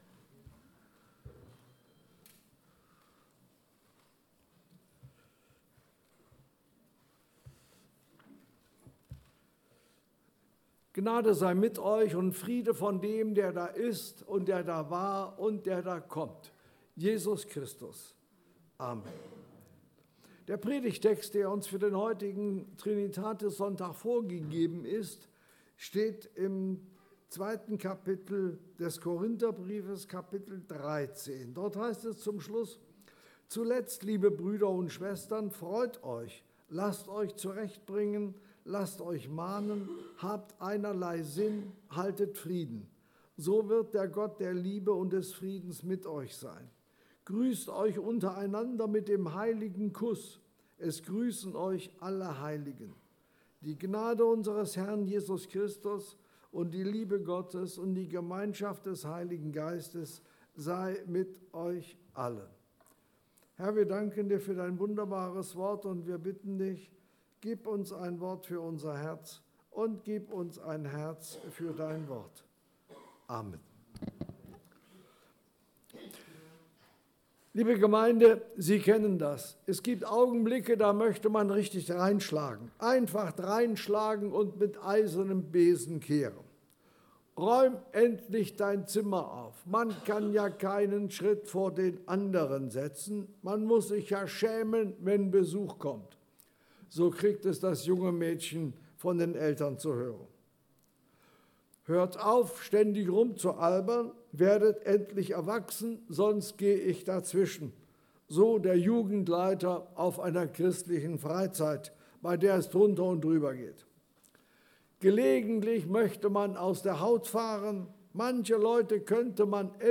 Korinther 13, 11-13 Dienstart: Gottesdienst « Wer ist Jesus?